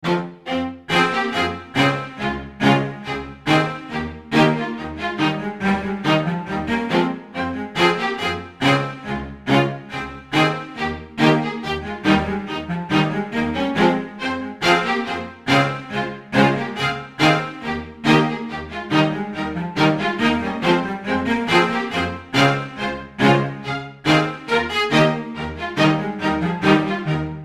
نغمة ميكس جديد مميز
Electronica